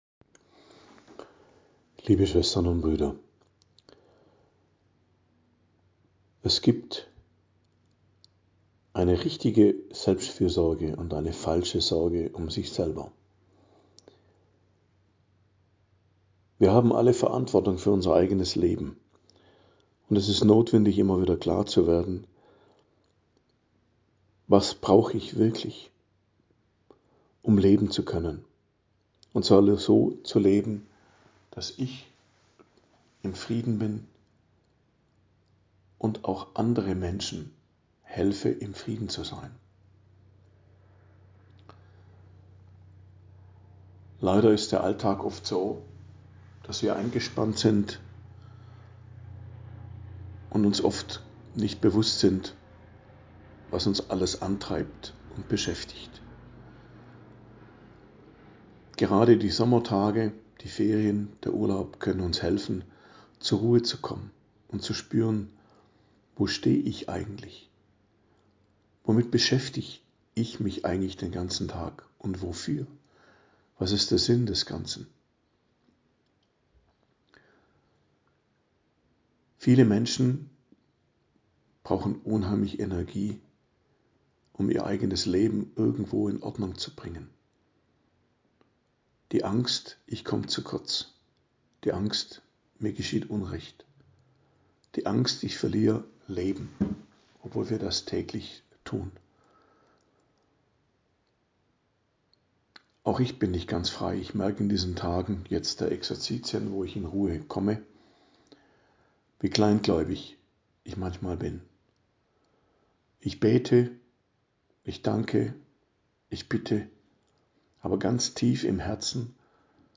Predigt zum 18. Sonntag i.J., 3.08.2025